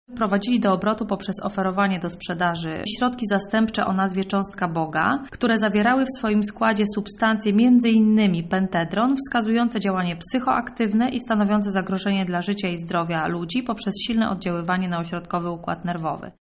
Mówi prokurator